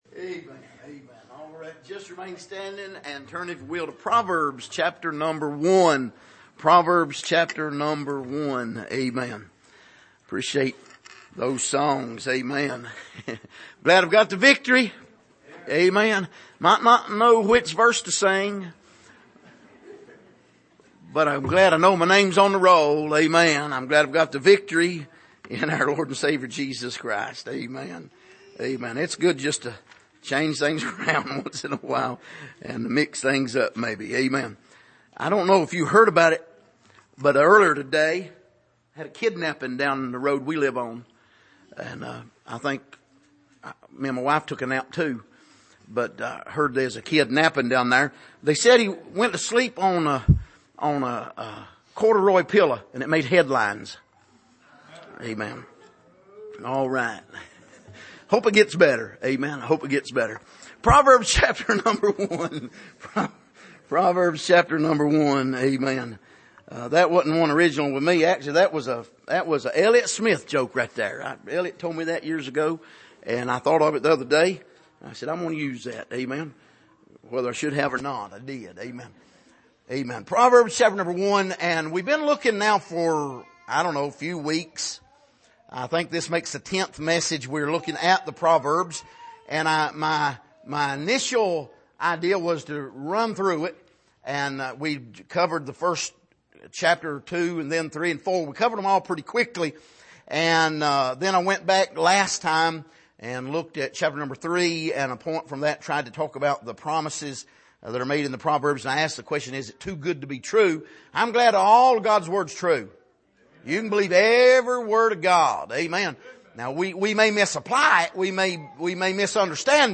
Passage: Proverbs 1:10-16 Service: Sunday Evening